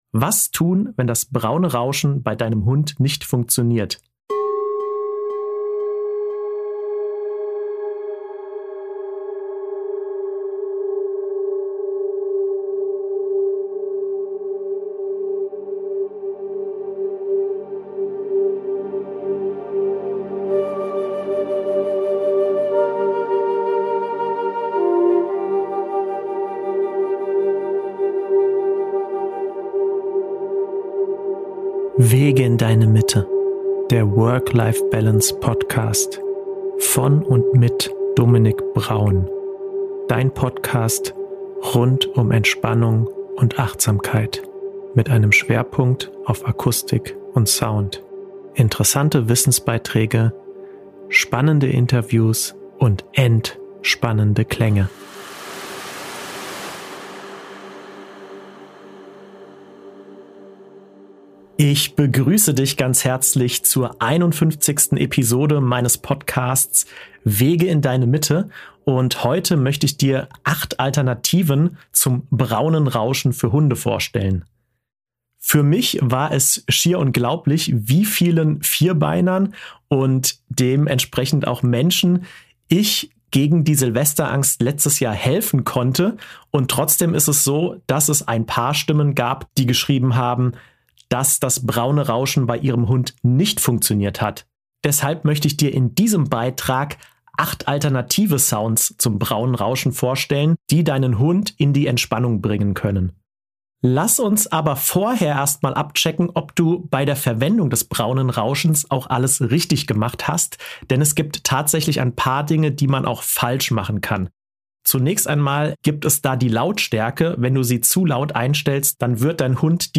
Bei den Klängen aus dieser Bestenliste handelt es sich um synthetische Rausch-Geräusche, Naturgeräusche und Musik.